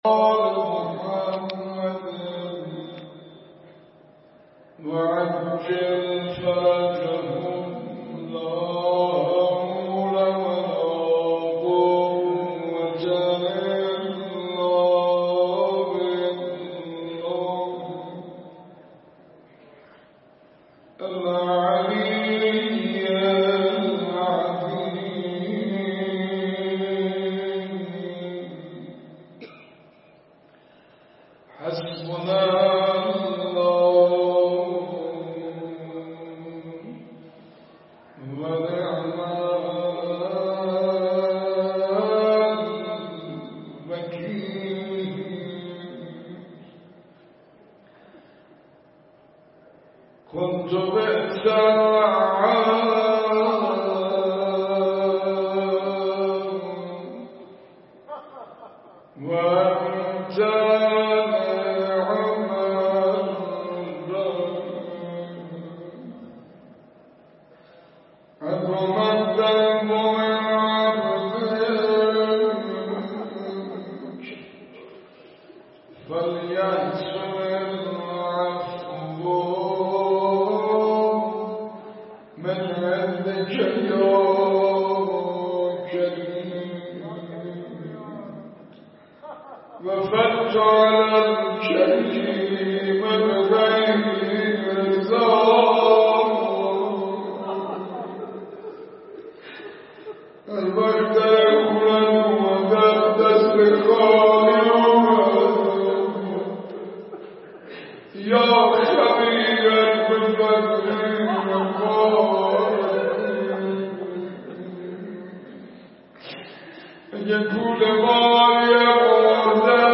مراسم مناجات خوانی ماه رجب
هیئت مدرسه